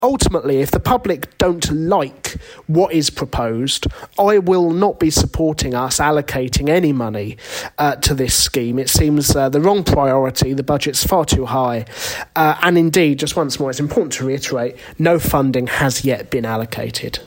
Independent Councillor James Giles talking about Fountain Roundabout in New Malden.